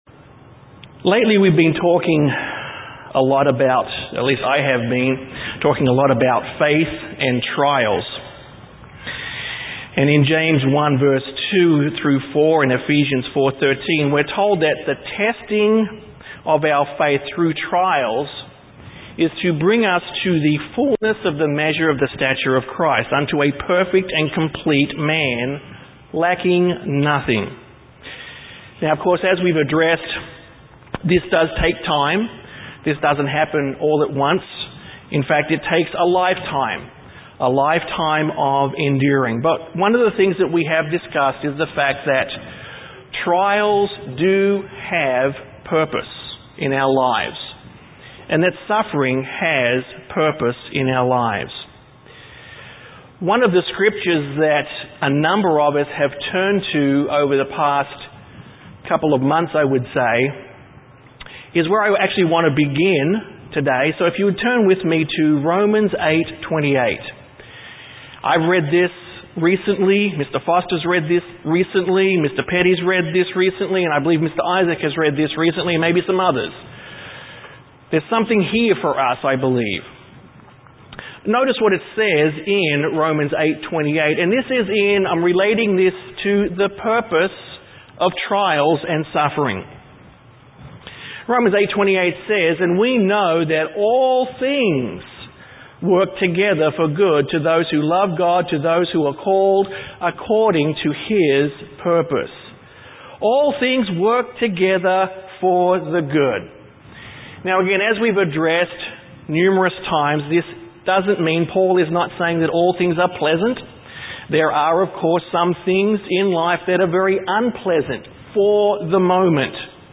This sermon touches on the Goodness of God, but mainly focuses on His Sovereignty as seen in the universe.
Given in San Antonio, TX